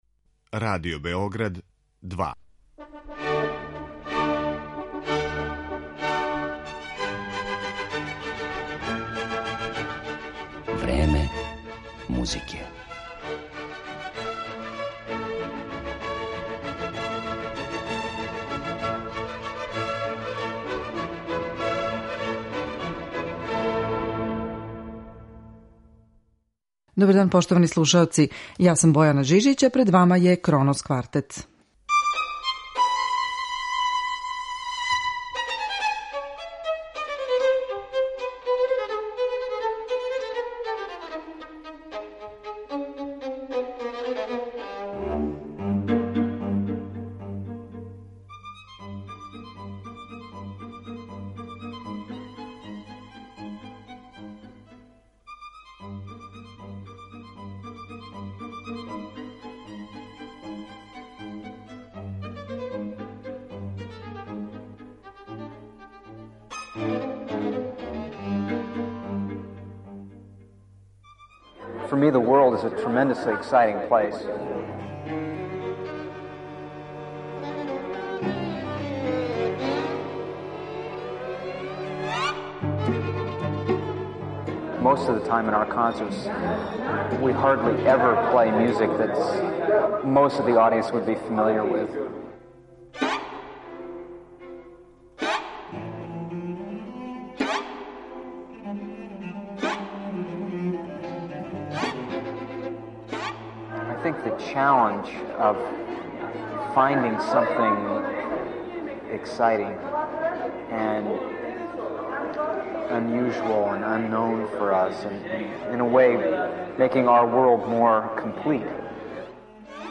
У емисији ћете моћи да чујете и серију интервјуа остварених са оснивачем и првим виолинистом ансамбла Дејвидом Херингтоном. Интервјуи су забележени у четири наврата: од 1994. године у Истанбулу, до 2012. у Новом Саду.